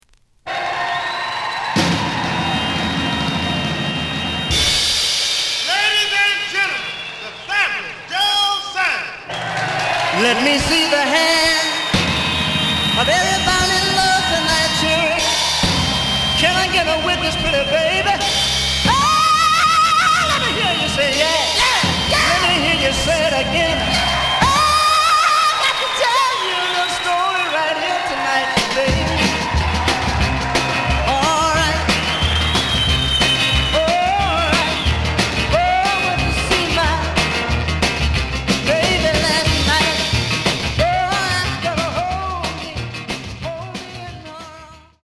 The audio sample is recorded from the actual item.
●Genre: Soul, 60's Soul
B side plays good.)